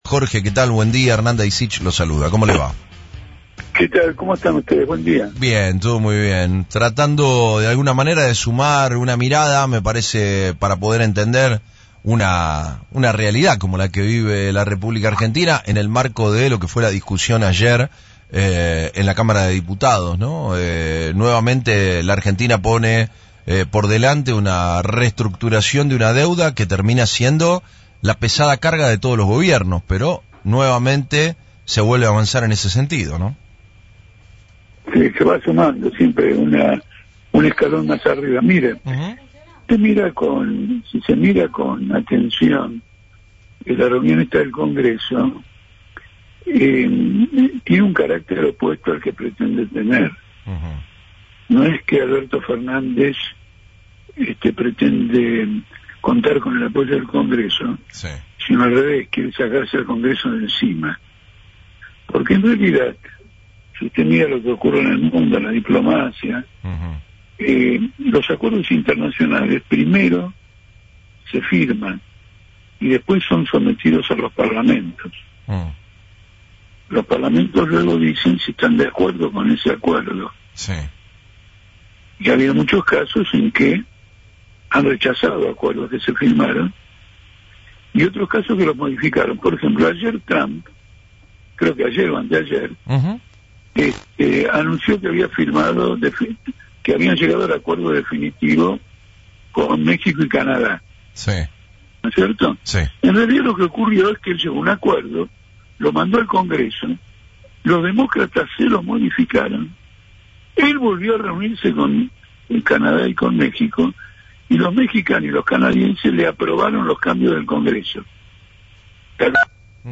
En dialogo con FRECUENCIA ZERO, el referente político de la izquierda y dirigente del Partido Obrero, Jorge Altamira, mostró una mirada diferente sobre la nueva ley votada por diputados, que otorga al gobierno la potestad de renegociar la deuda.